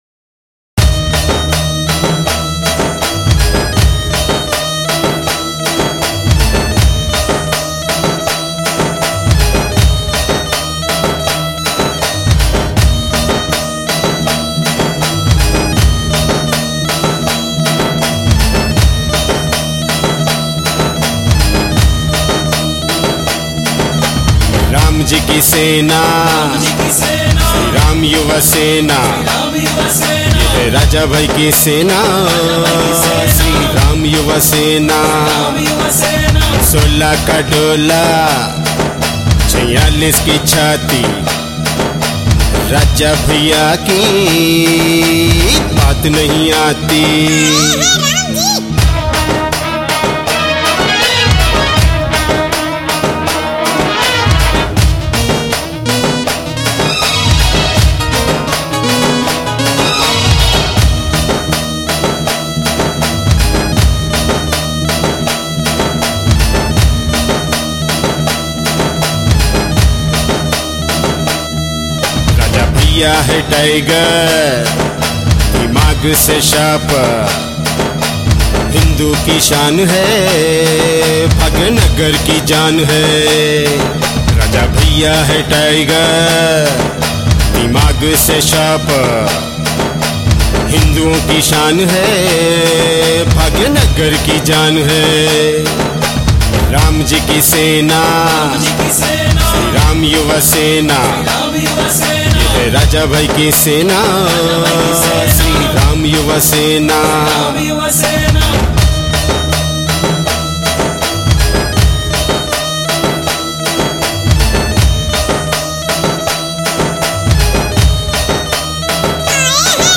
CategoryTelangana Folk Songs